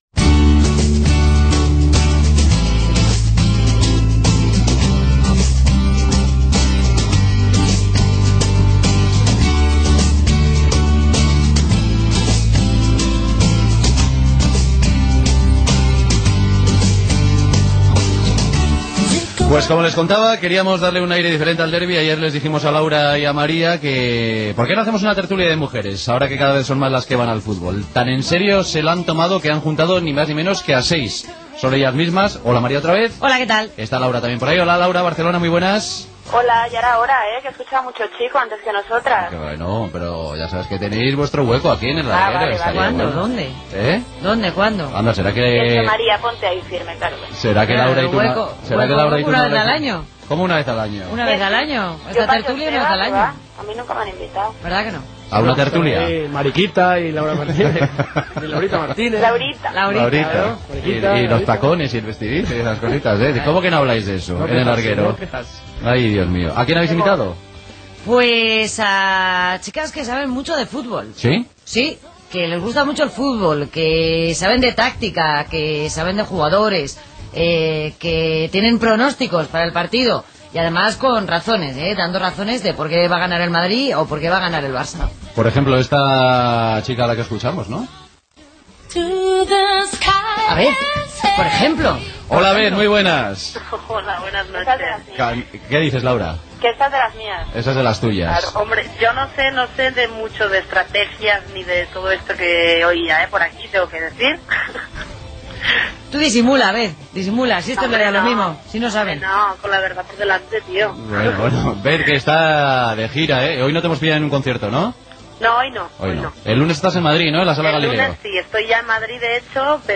Tertúlia abans del partit entre el Futbol Club Barcelona i el Real Madrid
Hora, identificació del programa, publicitat, indicatiu, promoció del programa "SER Digital", publicitat, indicatiu, publicitat, informació del partit Levante-Villareal, els números de la lliga de futbol professional masculí